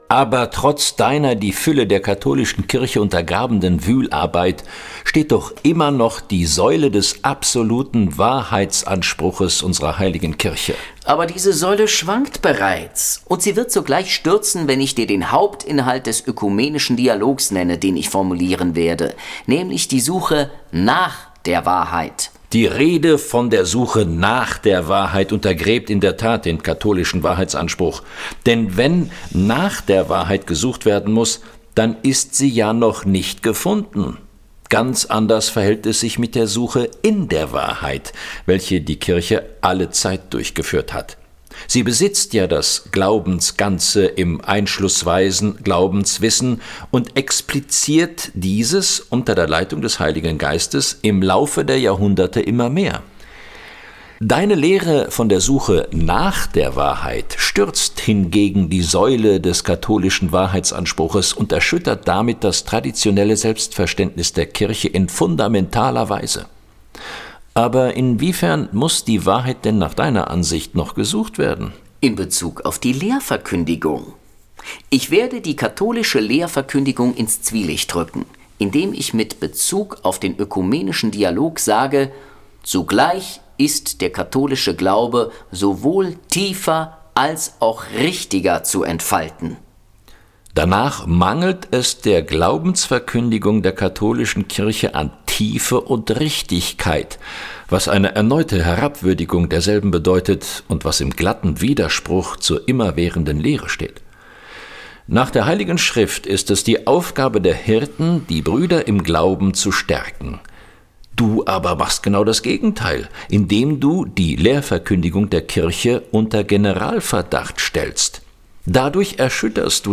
Ein Auszug aus dem Dialog: CD 2, Position 5/9: